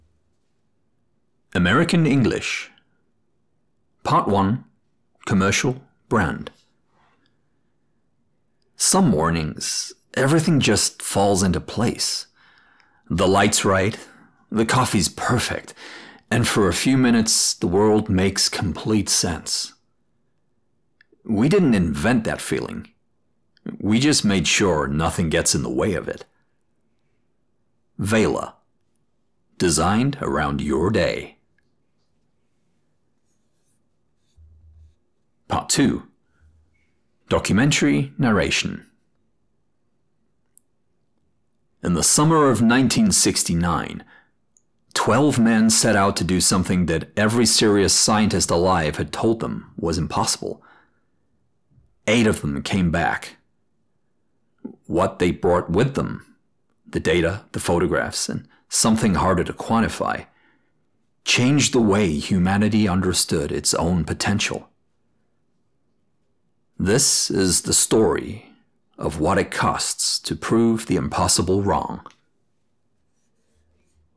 American English
Middle Aged
DEMO 4 — American English.mp3